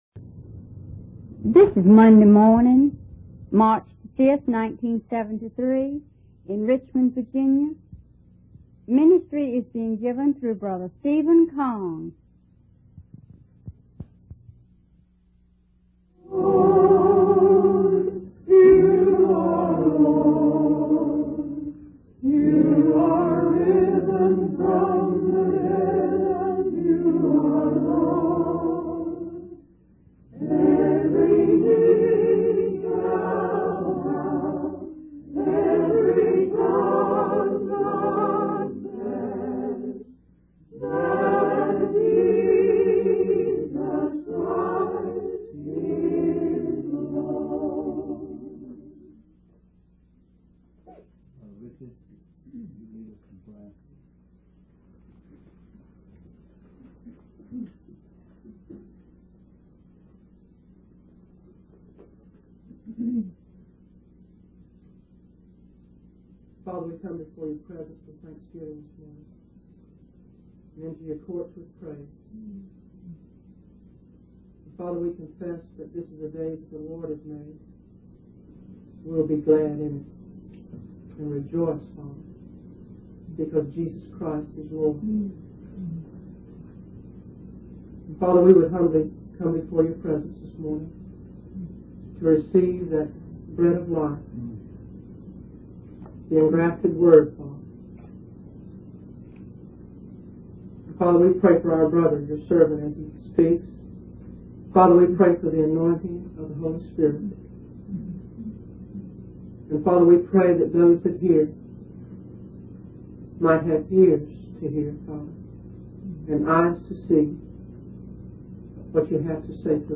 In this sermon, the preacher emphasizes the importance of grace in the life of a believer. He explains that grace is freely given by the Lord Jesus and does not require any payment or credit.
The preacher also highlights that grace goes beyond forgiveness and a right relationship with God, as it brings about a new birth and transformation. The sermon concludes with the preacher praying for the anointing of the Holy Spirit and for the listeners to have ears to hear and eyes to see what God has to say.